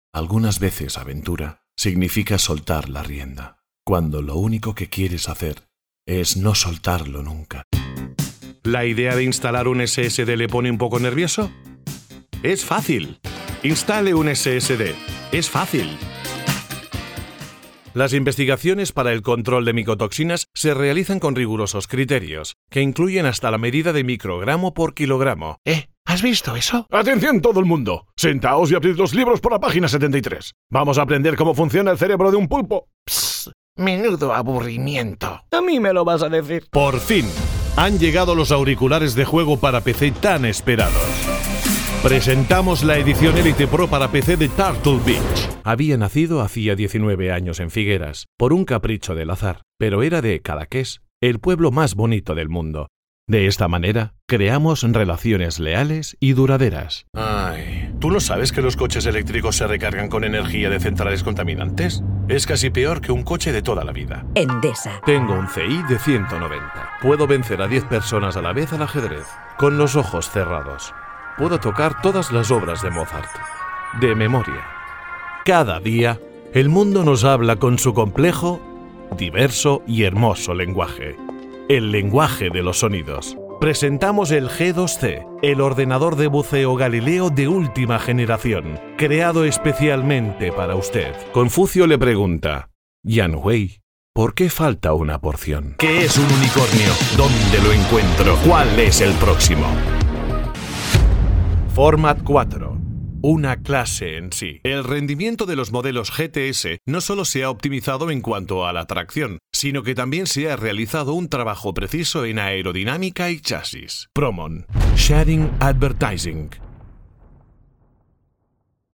kastilisch
Sprechprobe: Sonstiges (Muttersprache):